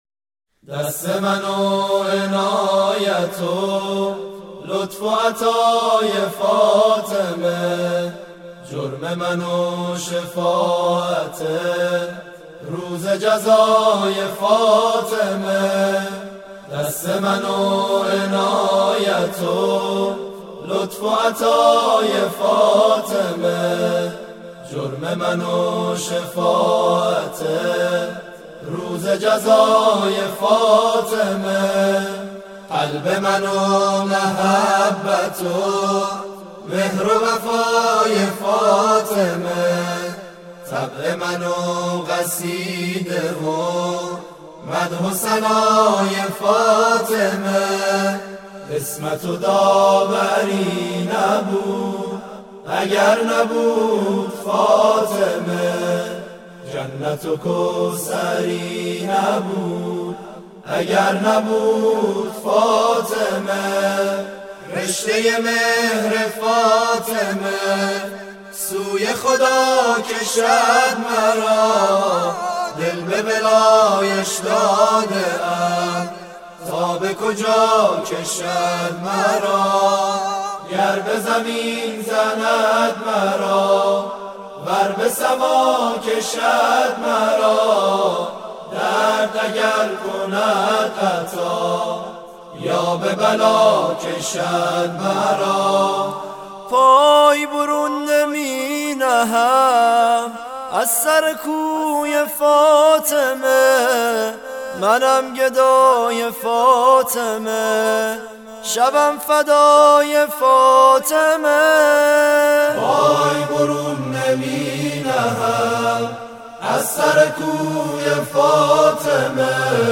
تواشیح_ منم گدای فاطمة(4)